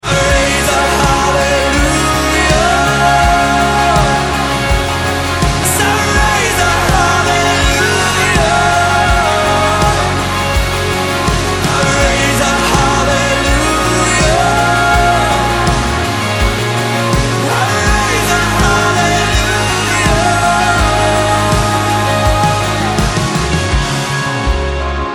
Ringtones Category: Pop